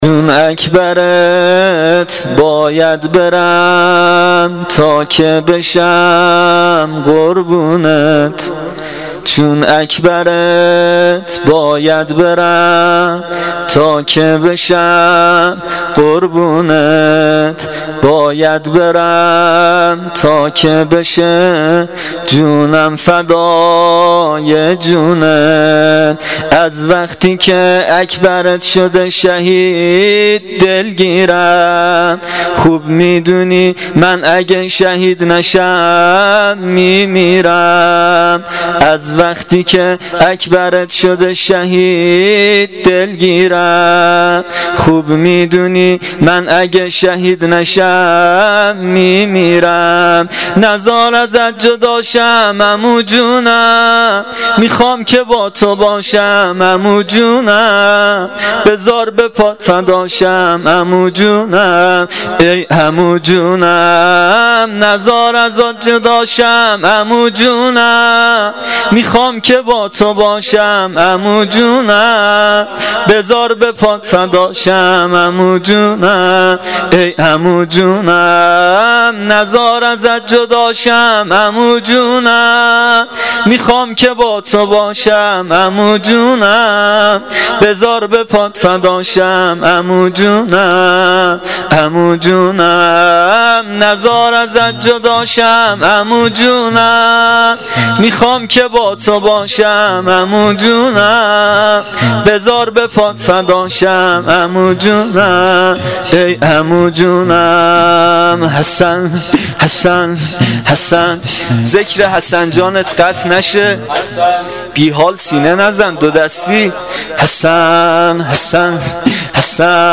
زمینه شب ششم محرم الحرام 1396